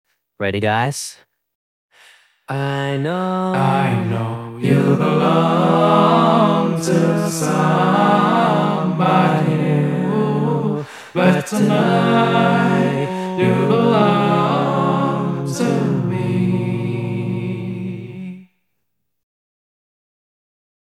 AI ACAPELLA COVER